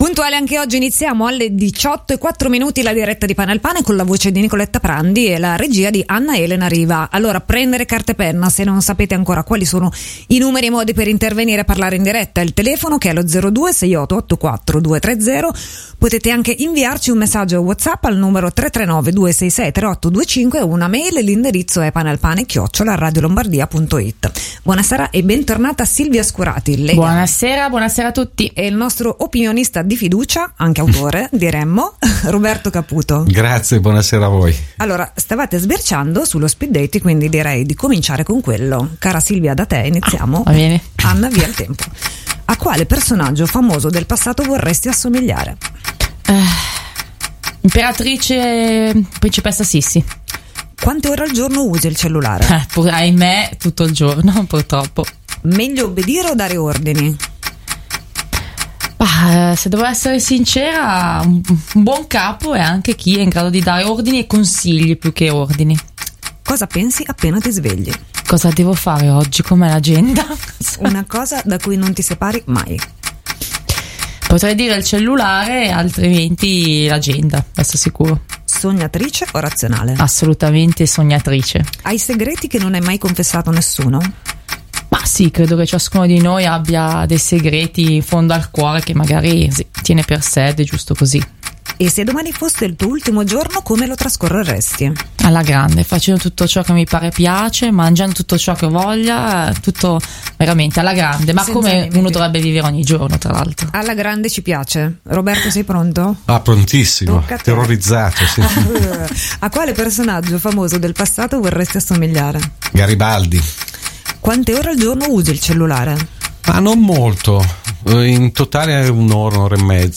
Intervento di diretta alla trasmissione Pane al Pane (Radio Lombardia, 12.2.2020)